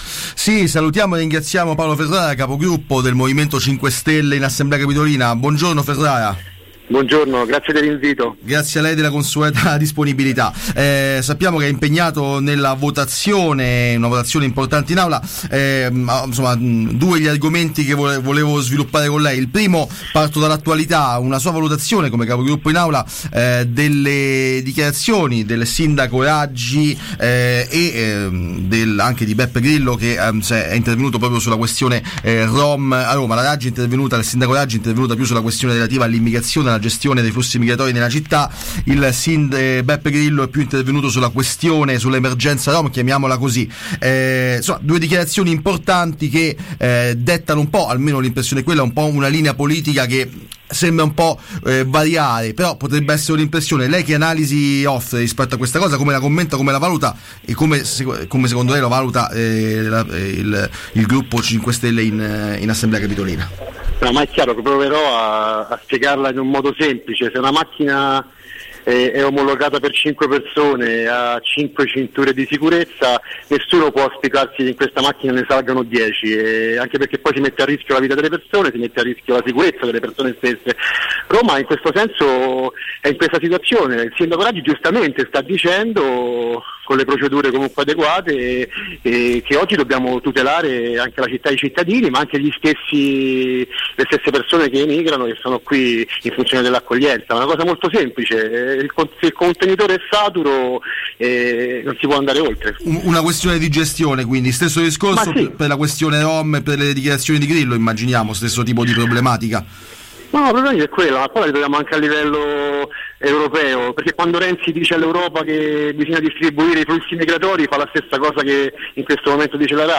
Immigrazione, rifiuti, stadio: la gestione di Roma a Cinque Stelle. Intervista a Paolo Ferrara. | Radio Città Aperta